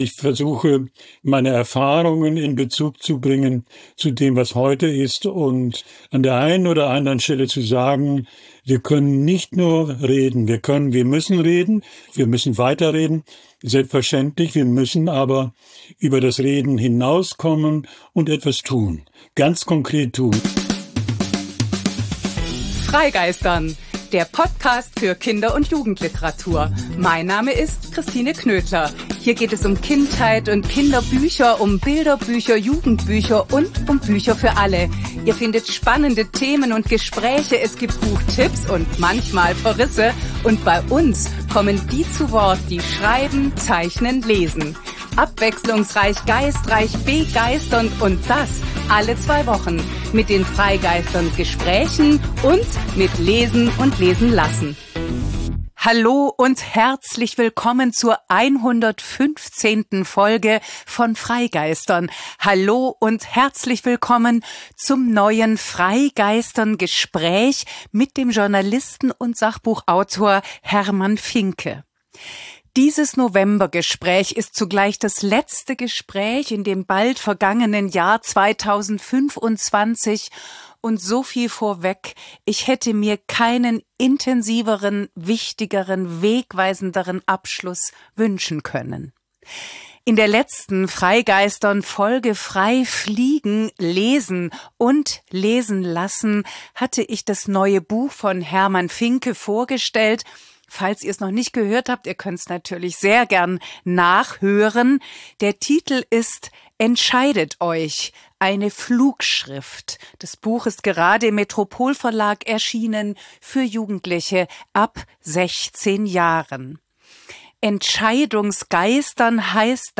Gespräch mit dem Journalisten und Sachbuchautor